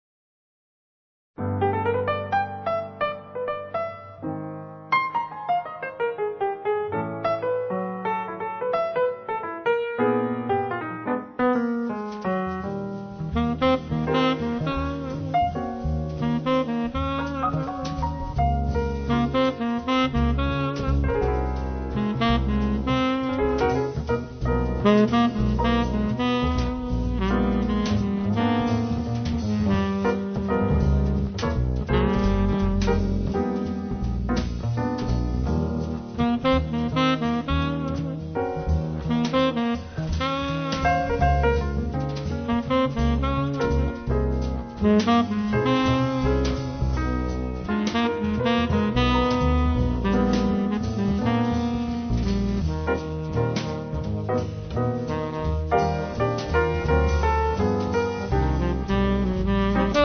Tenor & Soprano saxes